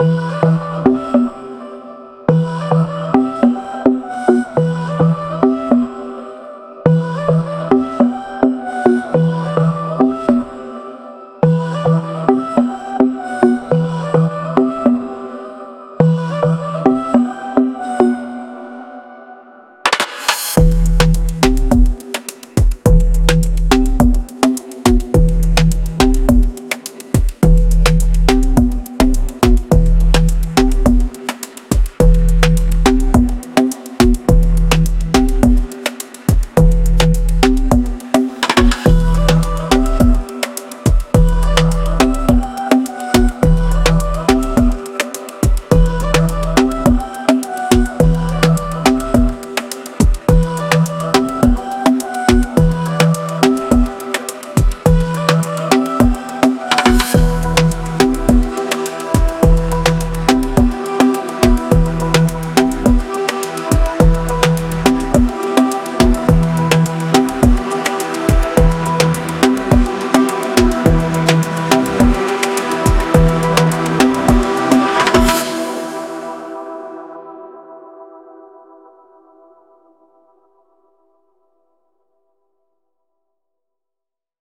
Groovy, Energetic
144 BPM